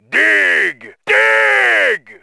RA2-奴隶矿场-采矿a.wav